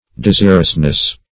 Desirousness \De*sir"ous*ness\, n.